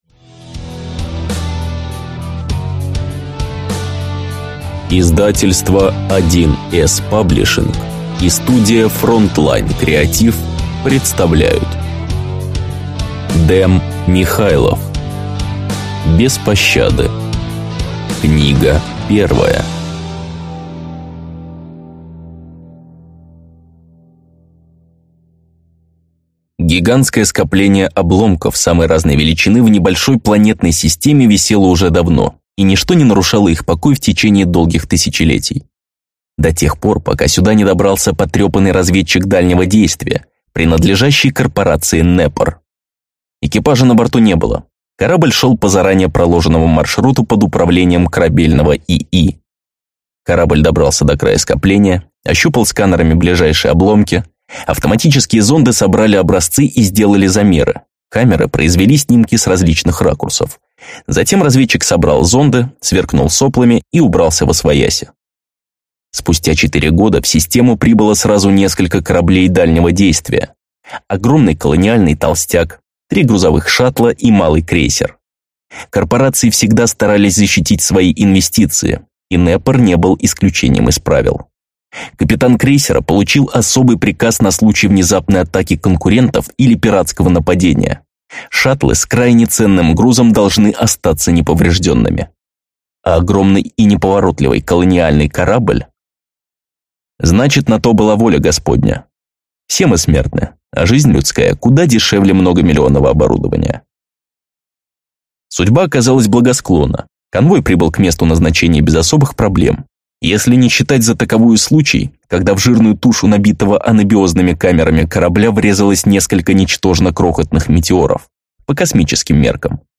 Аудиокнига Без пощады - купить, скачать и слушать онлайн | КнигоПоиск